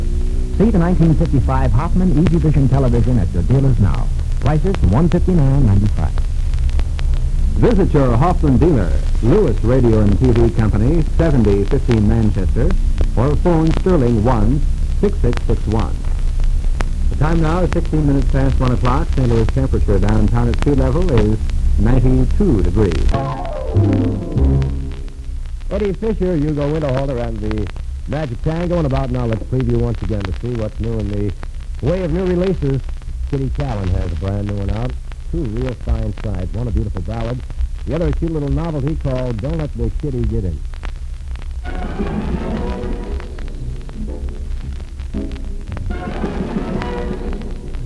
KXOK Unknown Announcer aircheck · St. Louis Media History Archive
Original Format aircheck